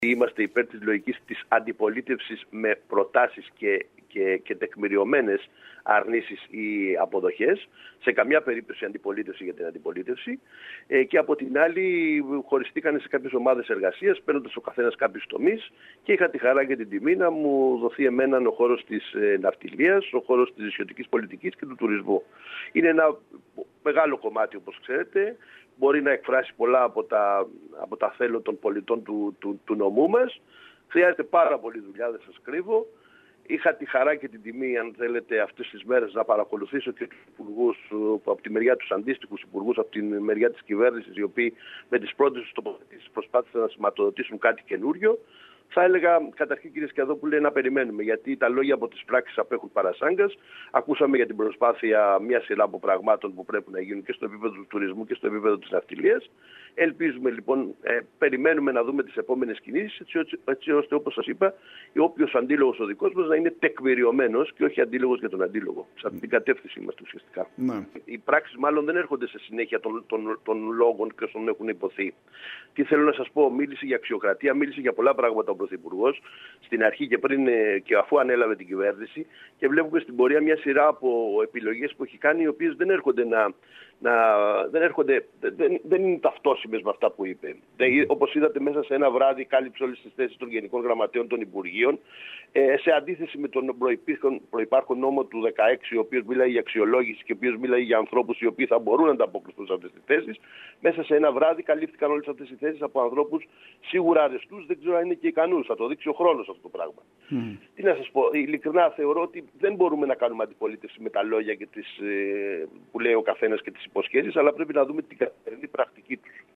Τις προγραμματικές δηλώσεις της κυβέρνησης σχολίασαν μιλώντας στην ΕΡΤ Κέρκυρας, οι βουλευτές του νησιού Στ. Γκίκας, Αλ. Αυλωνίτης και Δ. Μπιάγκης.